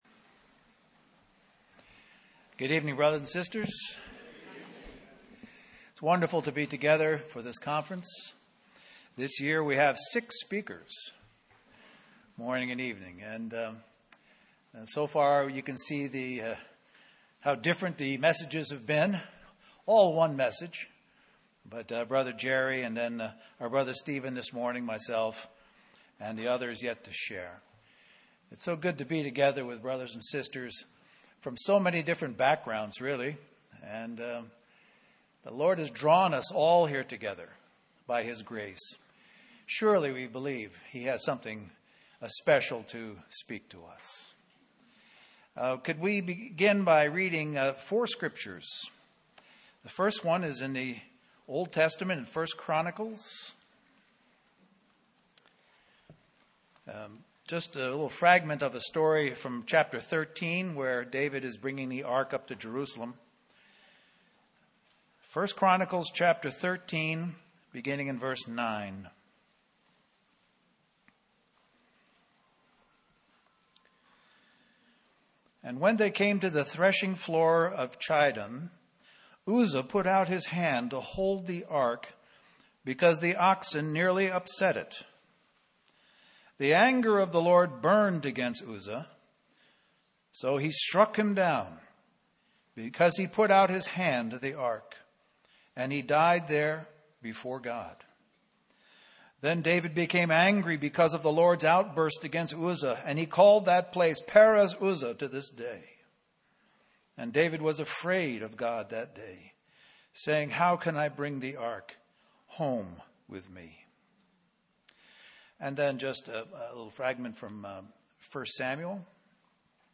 Christian Family Conference We apologize for the poor quality audio